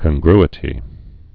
(kən-grĭ-tē, kŏn-)